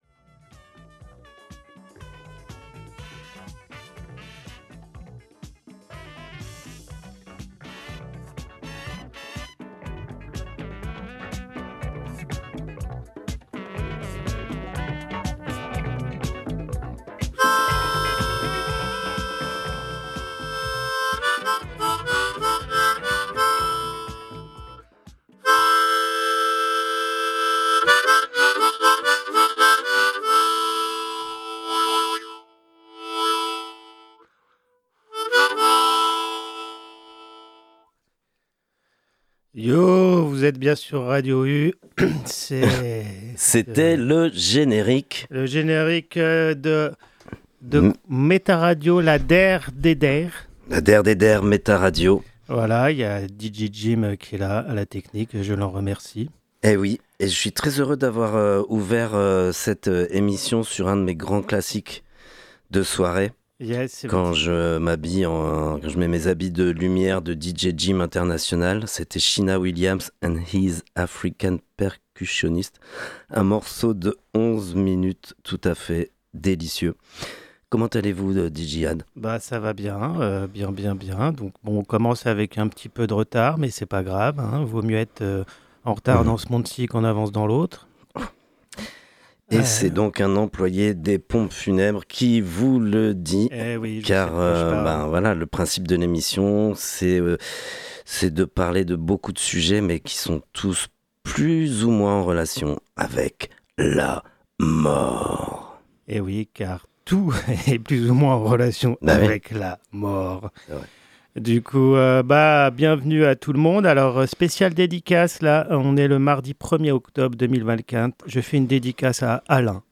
avec un extrait d’une interview